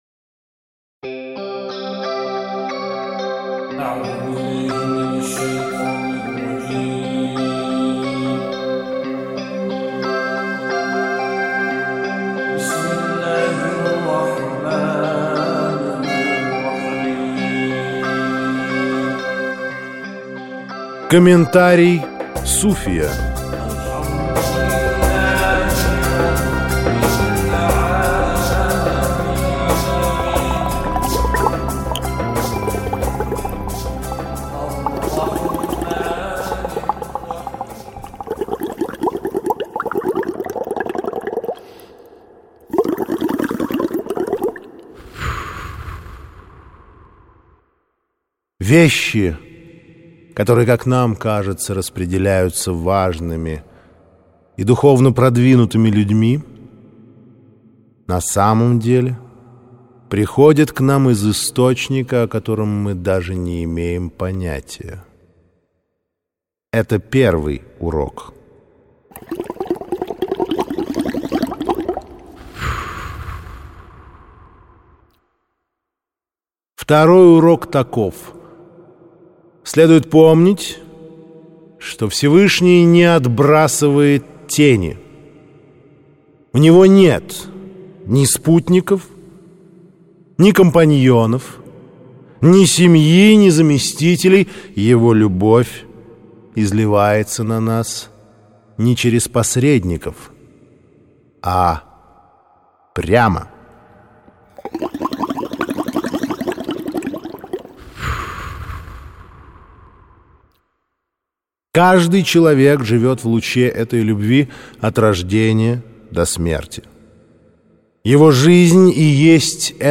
Аудиокнига П5: Прощальные песни политических пигмеев Пиндостана (сборник) | Библиотека аудиокниг